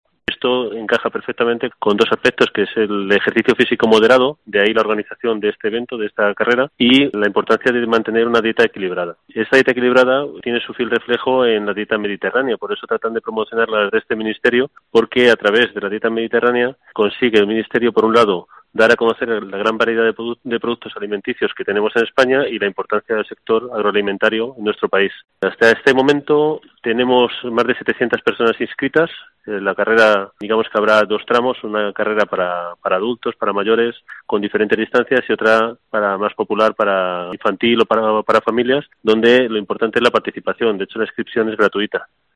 El Subdelegado del gobierno en Guadalajara, Angel Canales, señala los objetivos de esta cita deportiva y lúdica